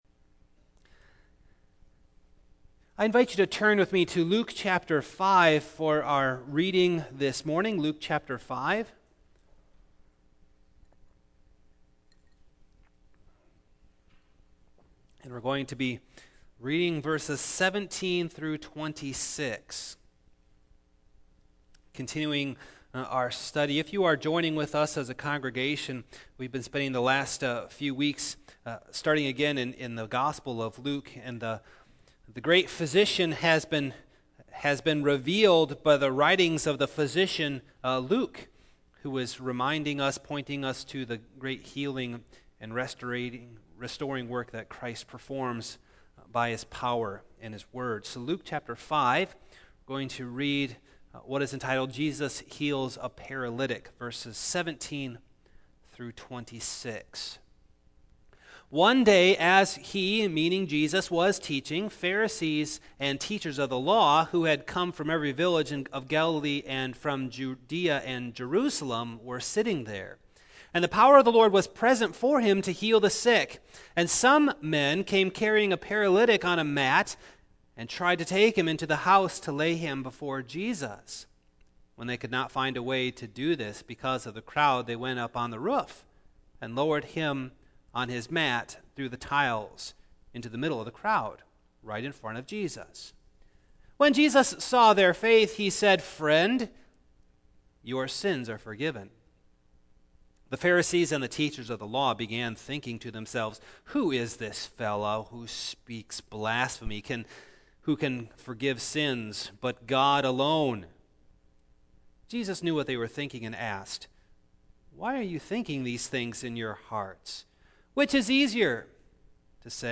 2016 The Lame will Leap Preacher